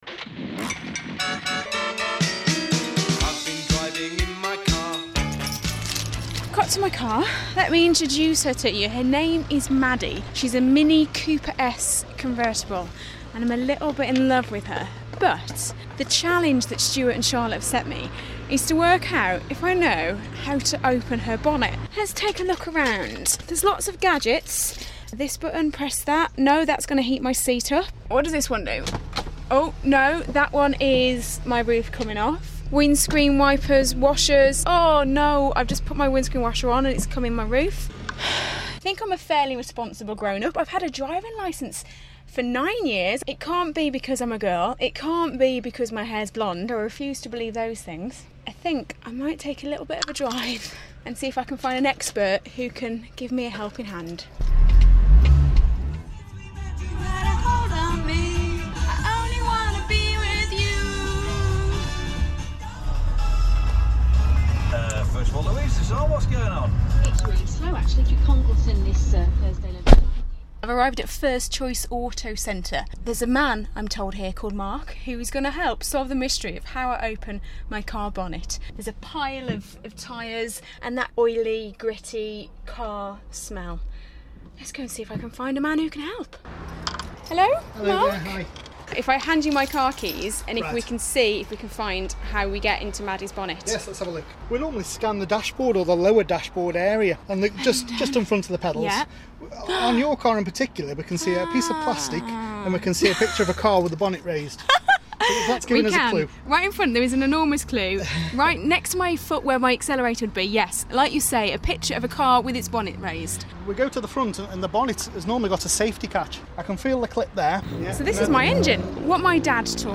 (Broadcast on BBC Radio Stoke, August 2014)